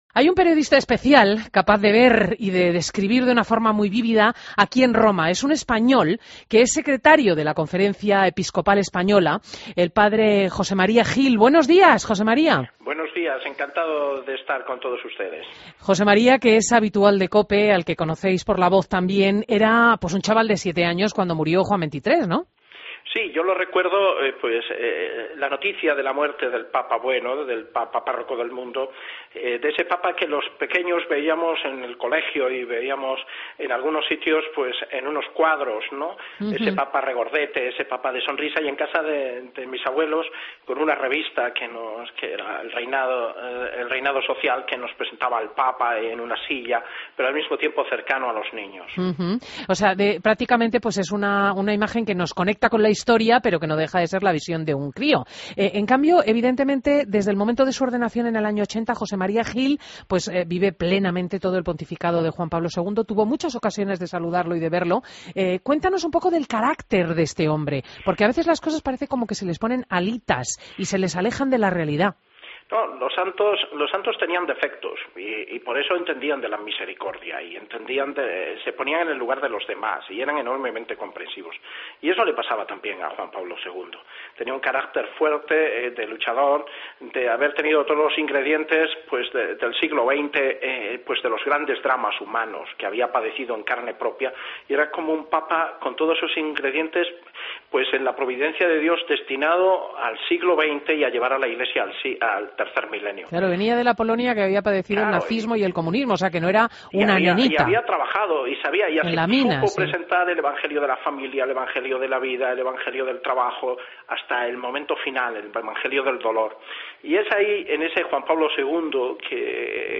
Entrevista a José María Gil Tamayo en Fin de Semana COPE